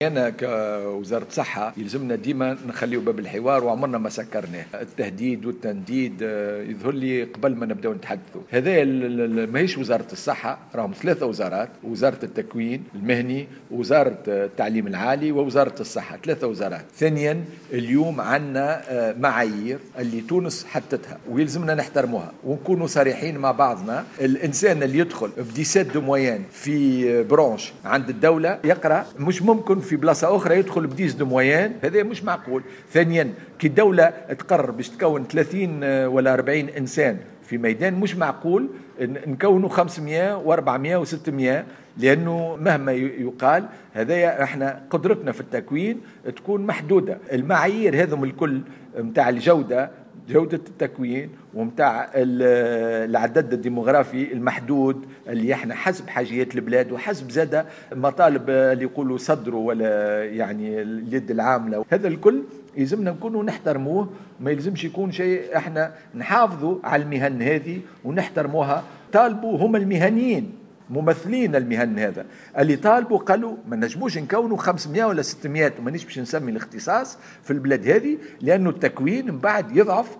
قال وزير الصحّة محمد الصالح بن عمار خلال اشرافه اليوم على اختتام الندوة الوطنية للصحة أن قرار تجميد تدريس الاختصاصات شبه الطبية في المؤسسات الخاصة يعتمد على عدة معايير مدروسة وضعتها الدولة ويجب احترامها مؤكدا أن هذا القرار اتخذ على مستوى 3 وزارات وهي وزارة التعليم العالي والصحة والتكوين المهني.